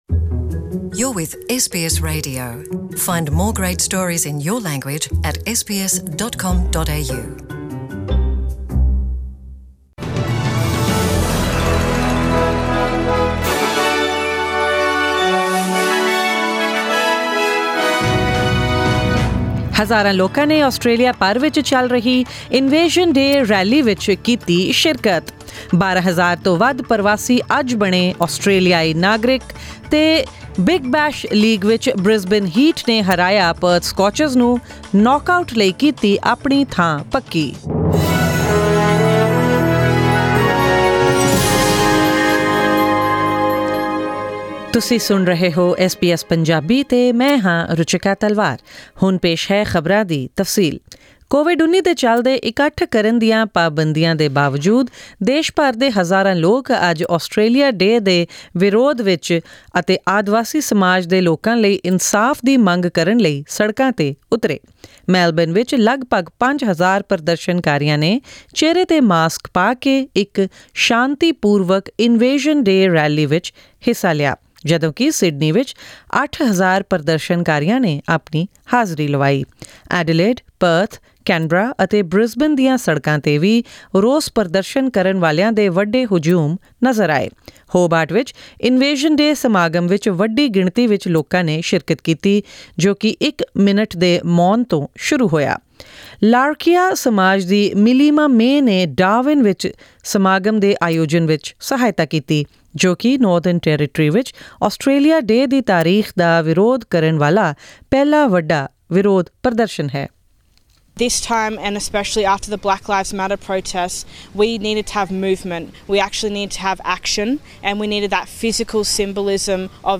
Scaled-down citizenship ceremonies have been held across the nation to welcome more than 12,000 migrants from 130 countries as Australia’s newest citizens. For this and more news on sports, forex rates and the weather forecast for tomorrow, tune into the bulletin.